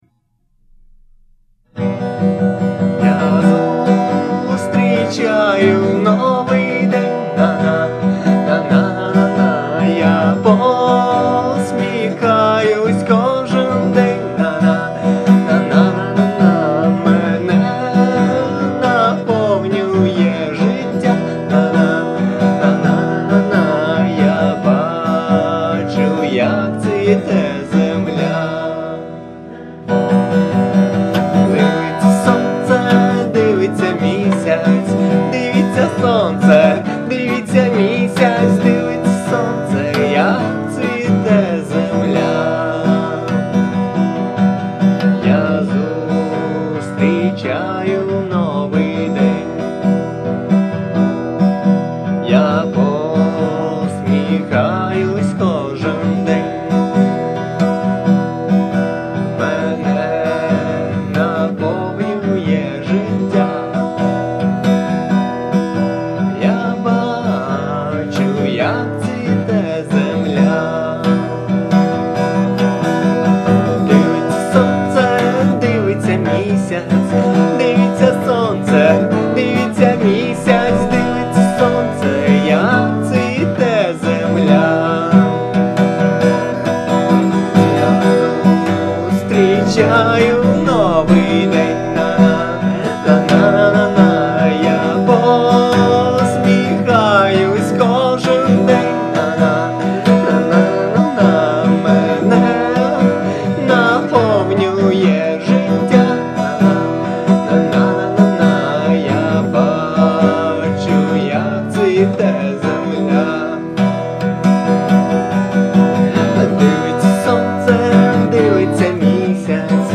акустика